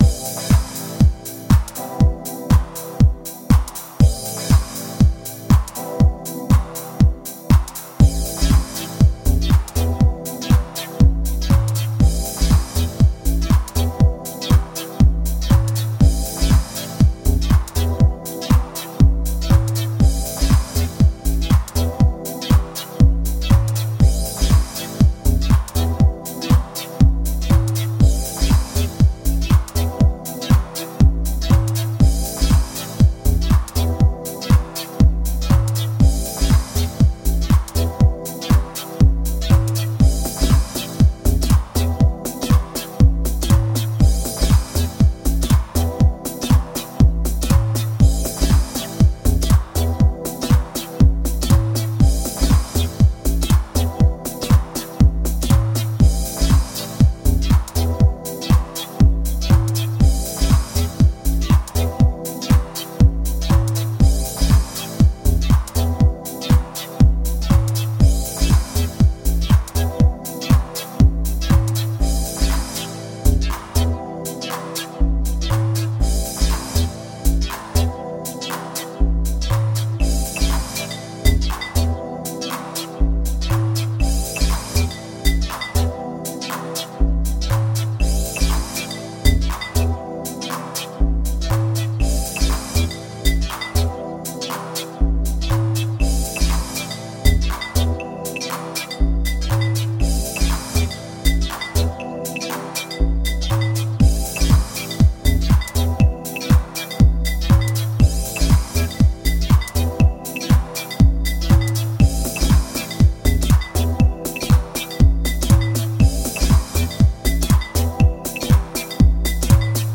Classic 1997 house cuts with disco samples